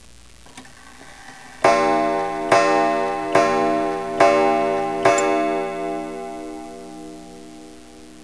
William S. Johnson Ogee Clock